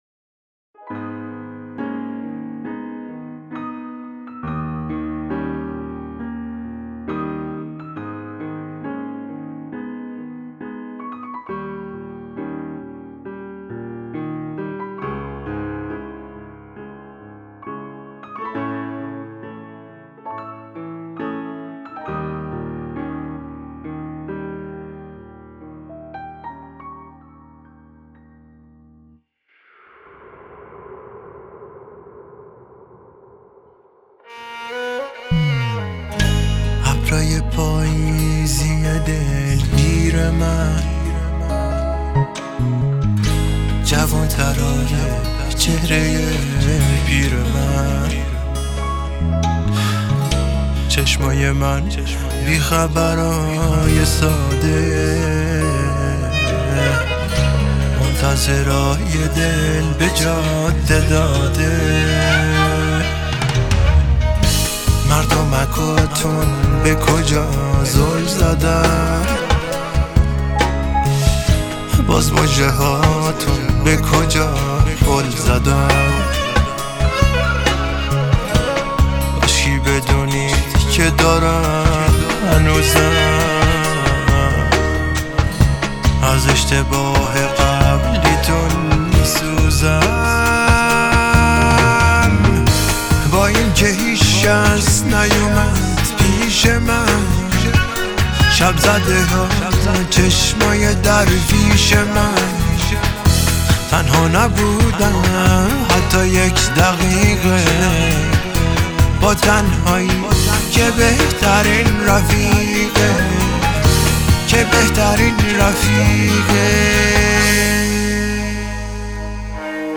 اگر به دنبال یک آهنگ غمگین و در عین حال شنیدنی هستید